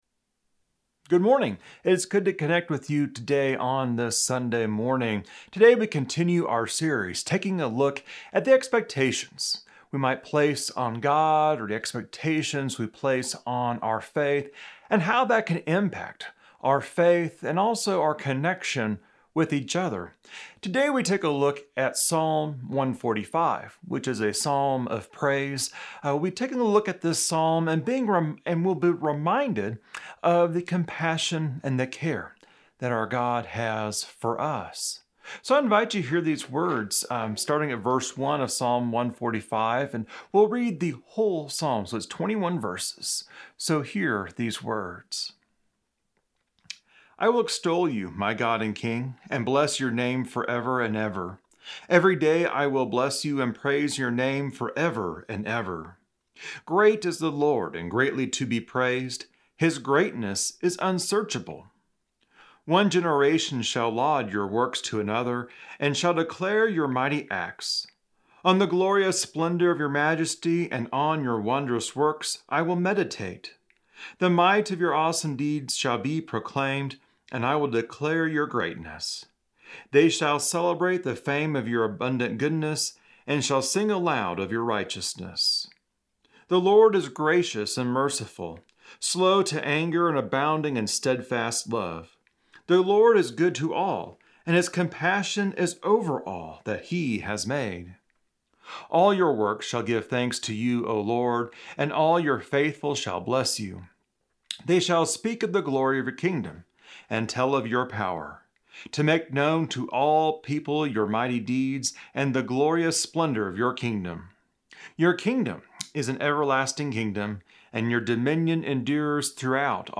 Continuing our September 2020 sermon series on expectations, we consider how God is compassionate when we may expect God to be indifferent to us. The scripture in focus is Psalm 142:1-21.